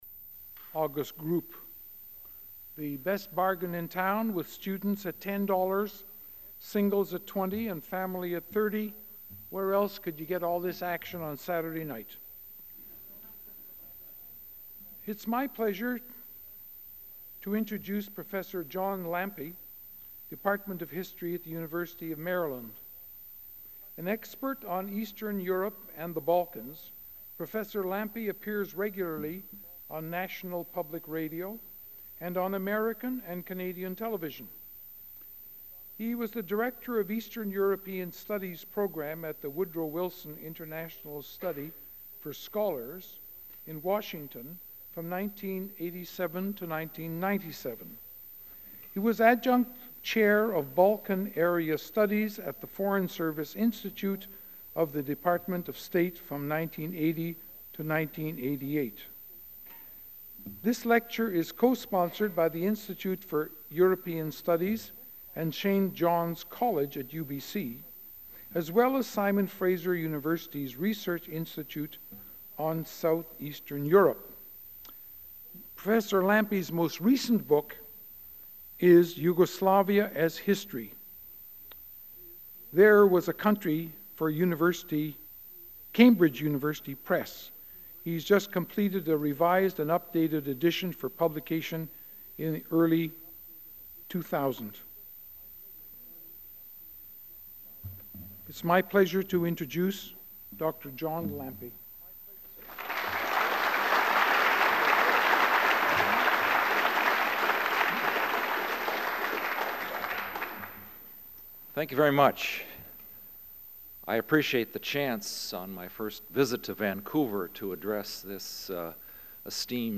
Vancouver Institute lecture